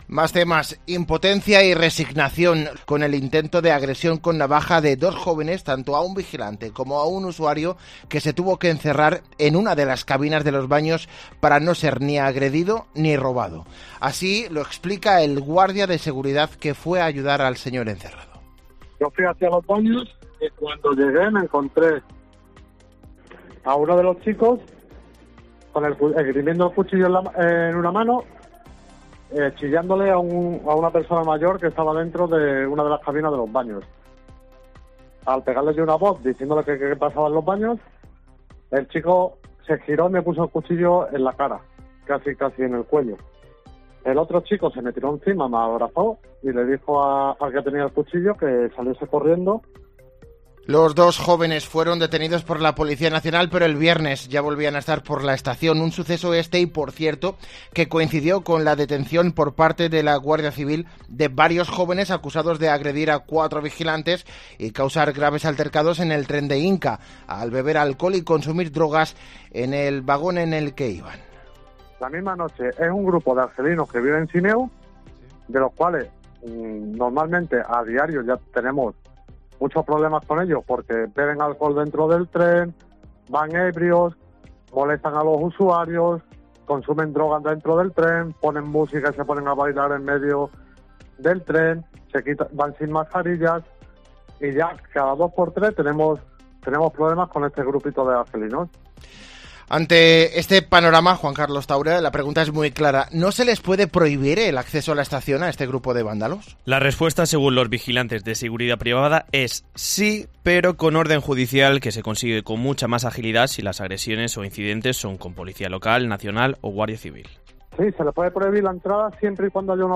Corte del Informativo Mediodía